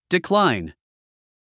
発音
dikláin　ディクライン